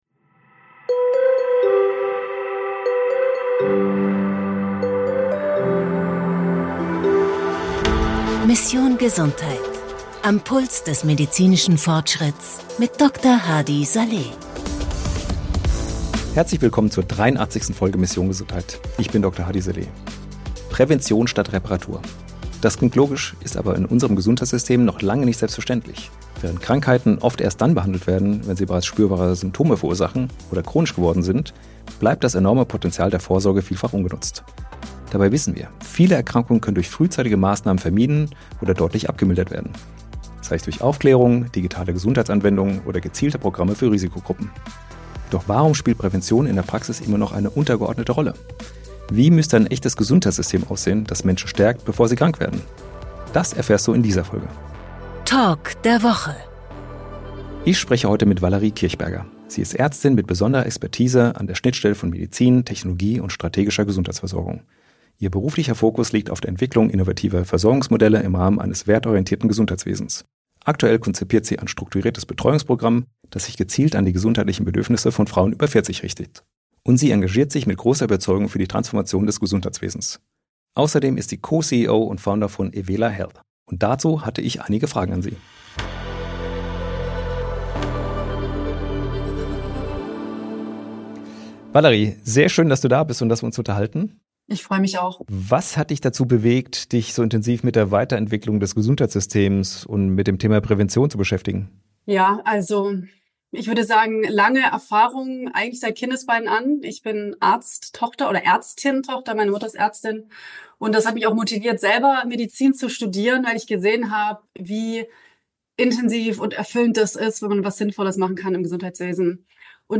Ein Gespräch über Value-Based Healthcare, Frauengesundheit, neue Versorgungsmodelle und die Frage: Wie sieht echte Gesundheitsversorgung in der Zukunft aus?